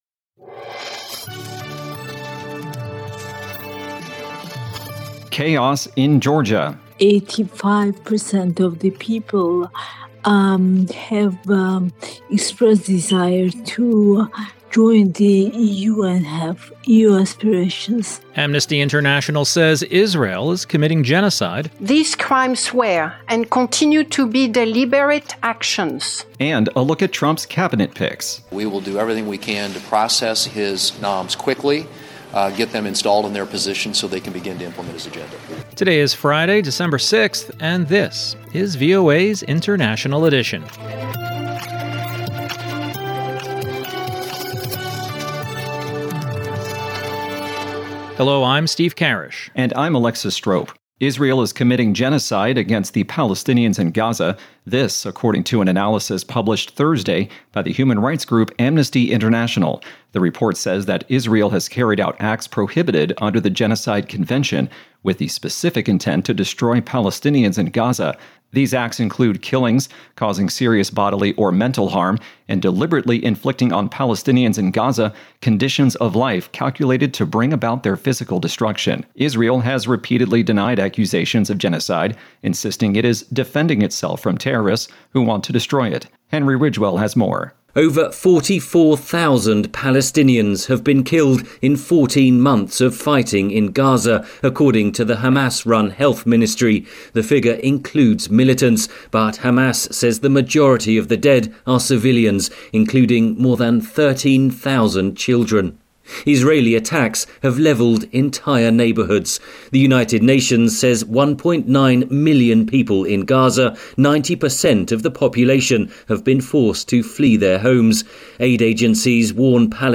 International Edition is VOA's premier global news podcast. Immerse yourself in the latest world events as we provide unparalleled insights through eye-witness accounts, correspondent reports, and expert analysis.